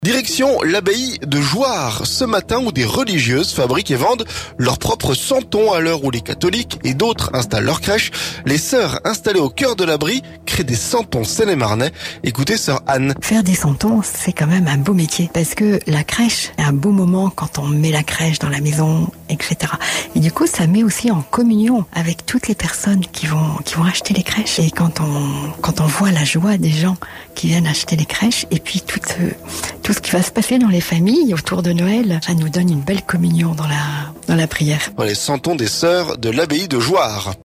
Direction l'abbaye de Jouarre ce vendredi, où des religieuses fabriquent et vendent leurs propres santons.